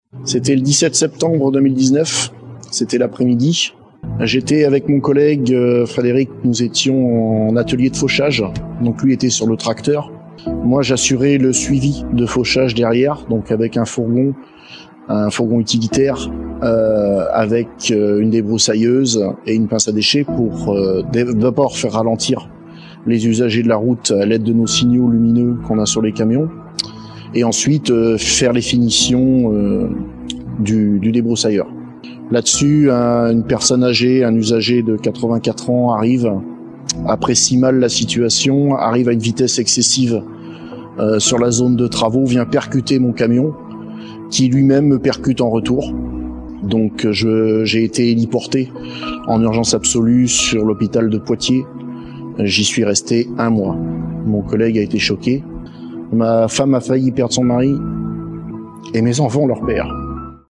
Ces témoignages feront l’objet d’une diffusion régulière sur les réseaux sociaux du Département de la Charente-Maritime.